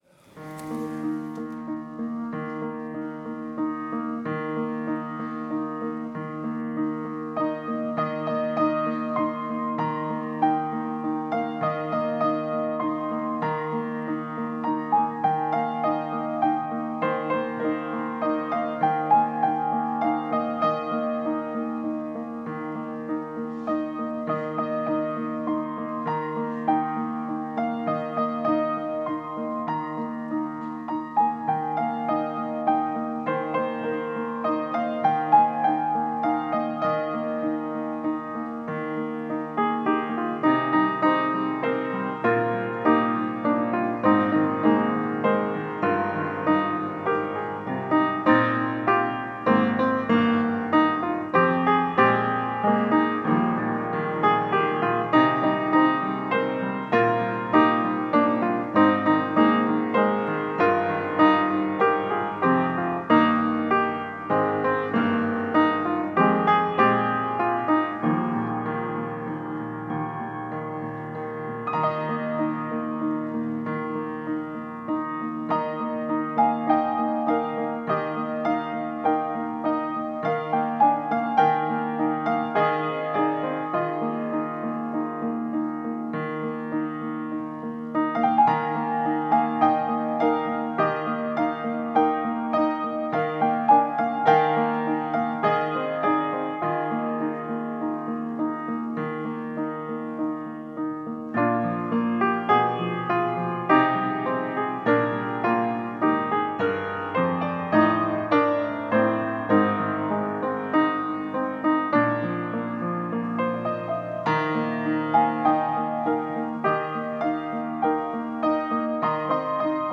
Passage: Genesis 3:15 Service Type: Sunday Morning